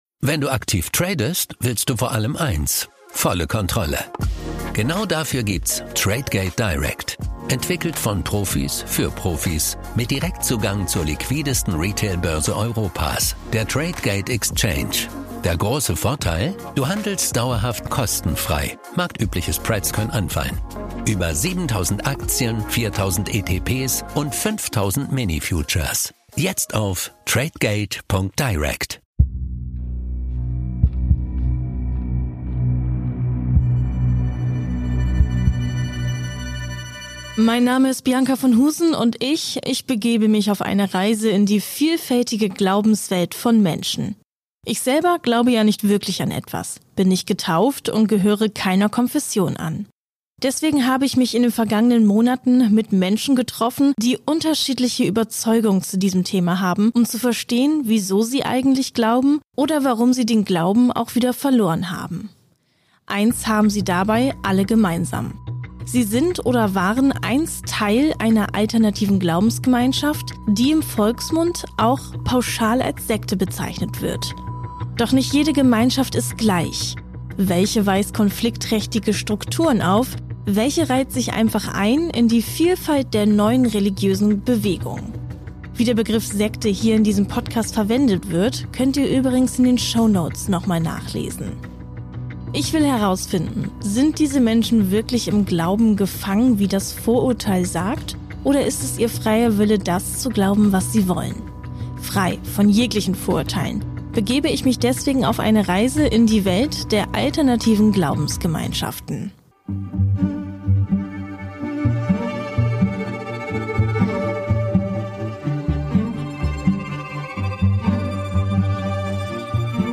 Sie gibt sich ökologisch und naturnah, folgt jedoch einer gefährlichen Ideologie. Gemeinsam mit einem Weltanschauungsexperten finden wir heraus, was hinter der Anastasia-Bewegung steckt, die auf russischen Romanen basiert und antisemitische Vorurteile bedient.